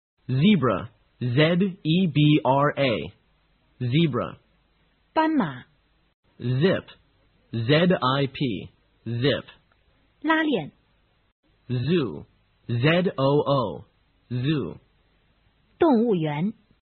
小学英语词汇听力记忆法:Z开头MP3音频下载,《小学英语词汇听力记忆法》利用真人发音帮助学生用耳朵记忆单词。